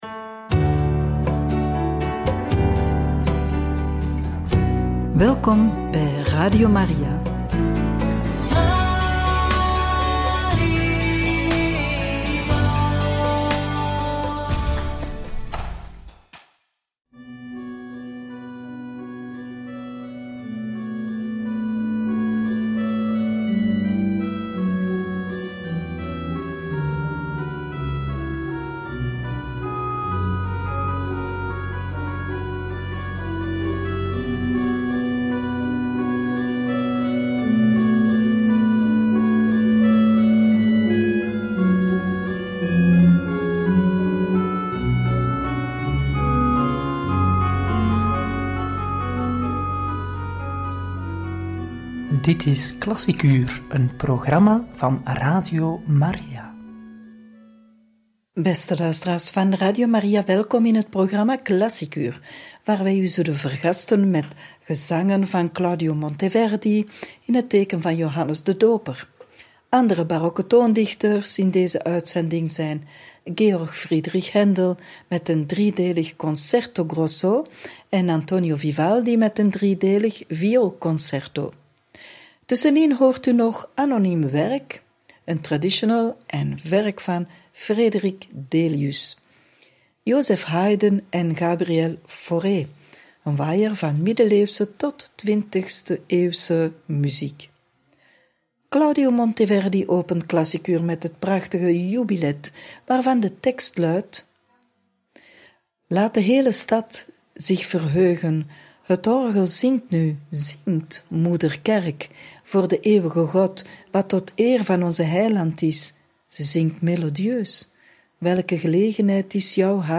Een waaier van muziek uit de middeleeuwen tot muziek uit de 20ste eeuw! – Radio Maria